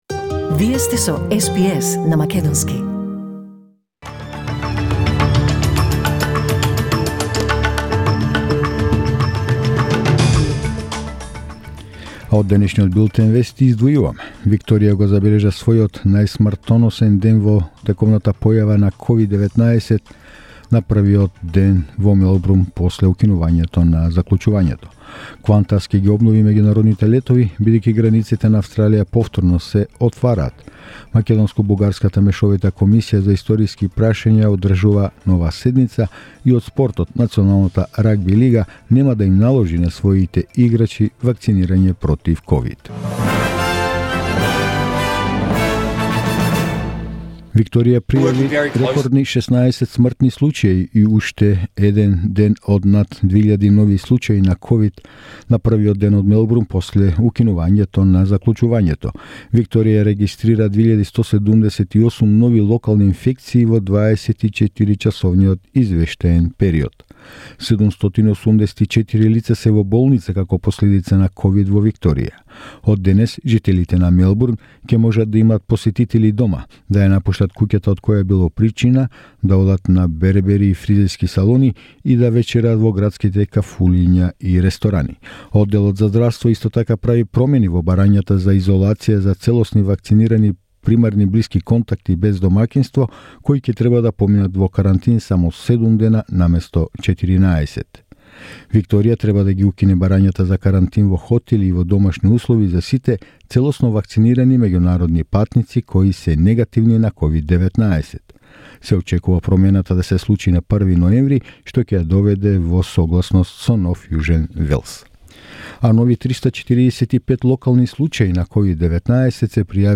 SBS News in Macedonian 22 October 2021